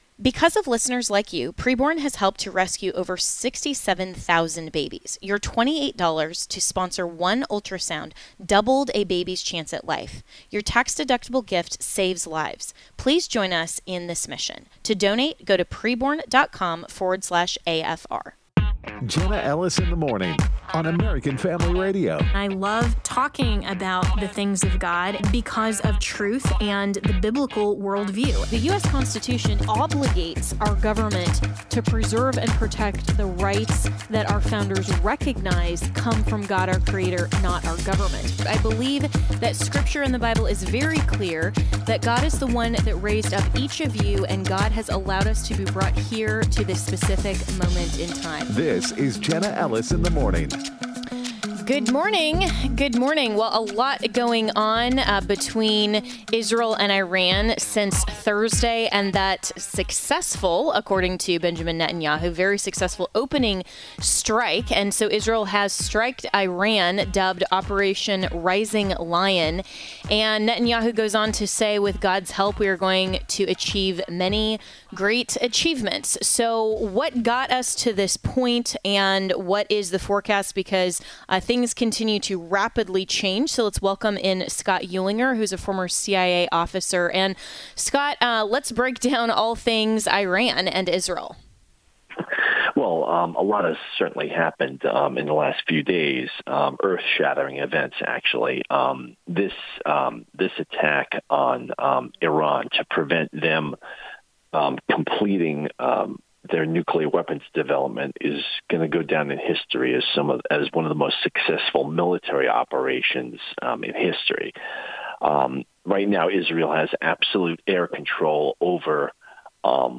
A Live Report from Jerusalem on the Israel-Iran Conflict
Joel Rosenberg of All Israel News joined the program from Jerusalem with a live report on the situation in Israel.